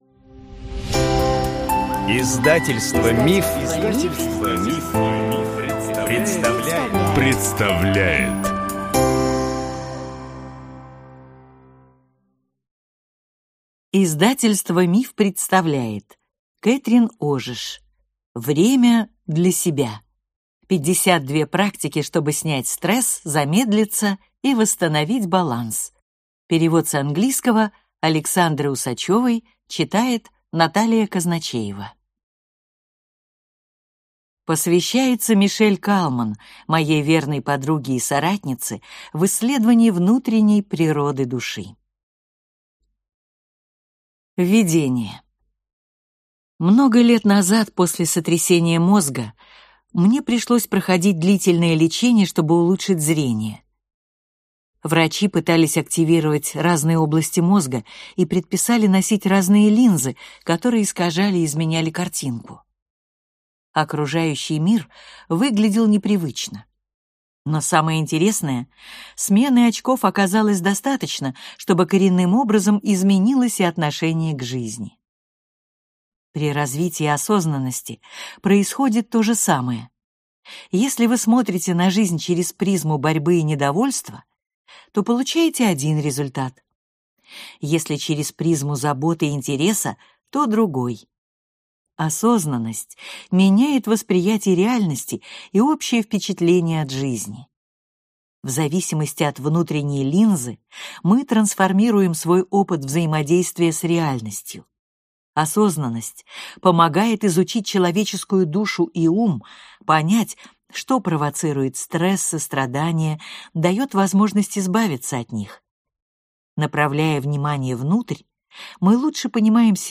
Аудиокнига Время для себя. 52 практики, чтобы снять стресс, замедлиться и восстановить баланс | Библиотека аудиокниг